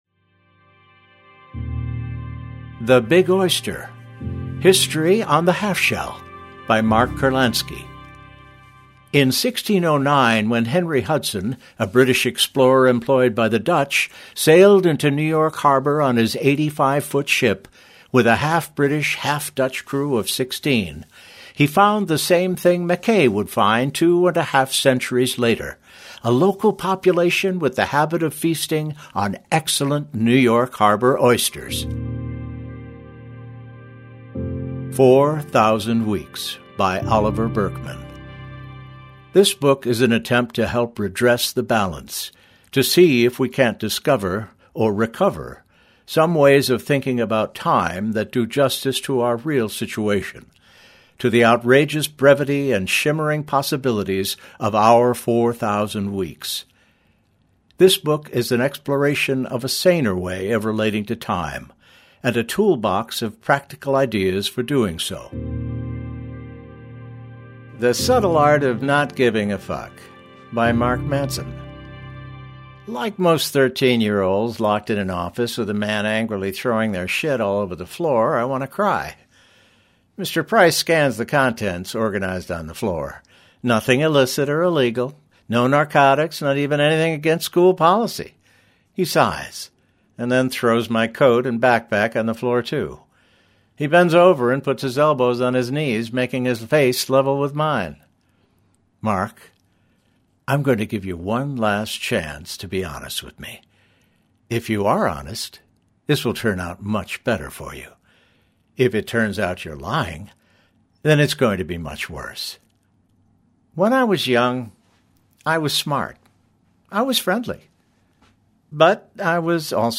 Audiobooks
English - USA and Canada
Middle Aged